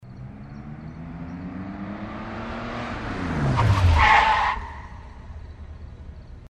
Звуки тормозов машины
На этой странице собраны звуки тормозов машин в разных ситуациях: от резкого экстренного торможения до плавного замедления.